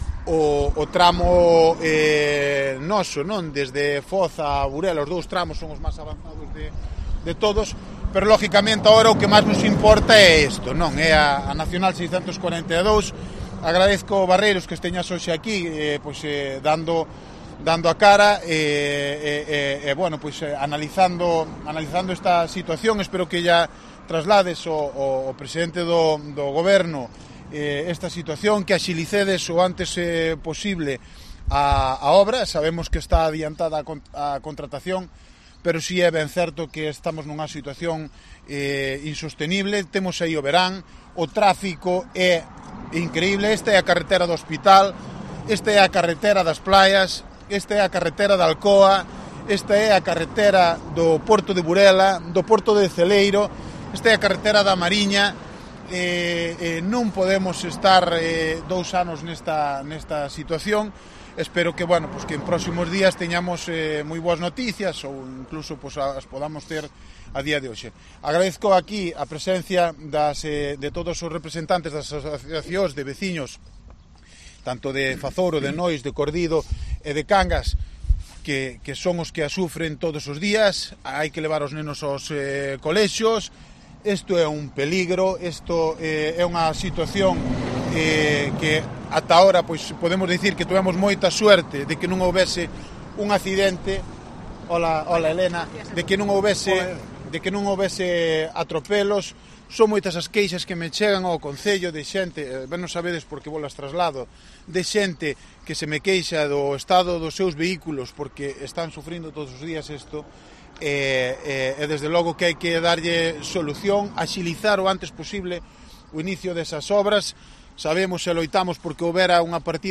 Encuentro de Barreiro con vecinos y cargos del PP